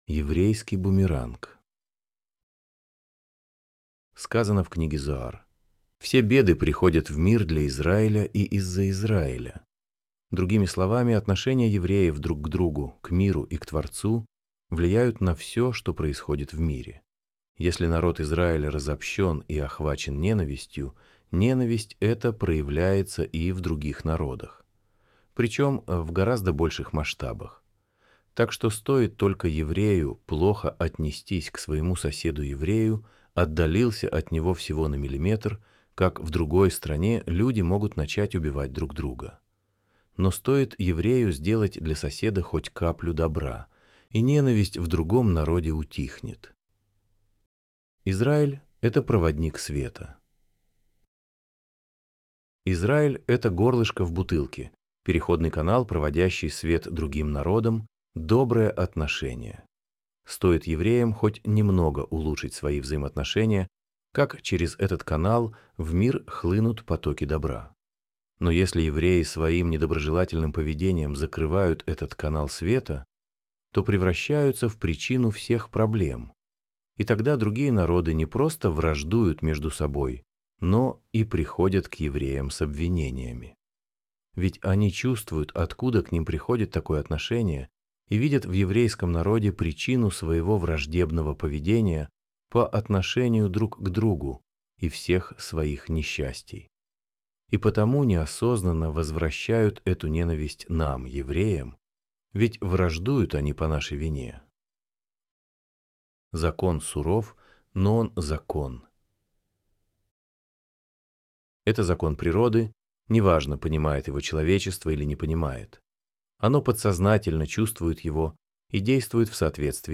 Аудиоверсия статьи